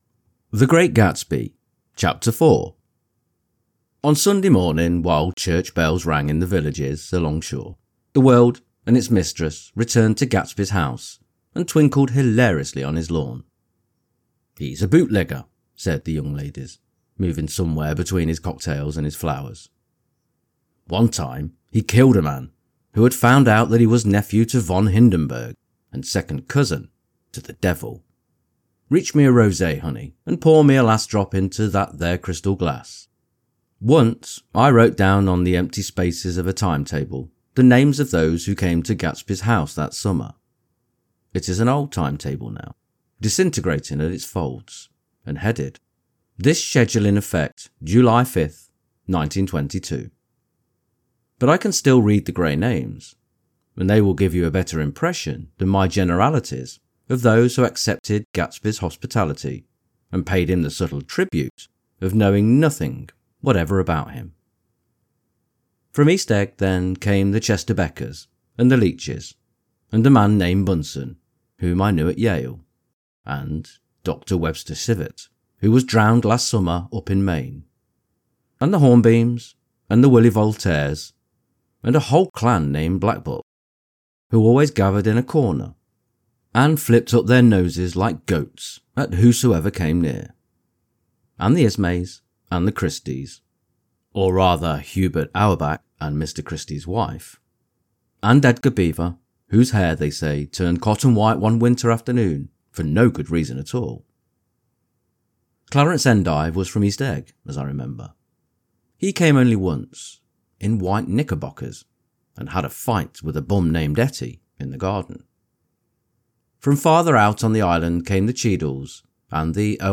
The Great Gatsby Audio-book – Chapter 4 | Soft Spoken English Male Full Reading (F.Scott Fitzgerald) - Dynamic Daydreaming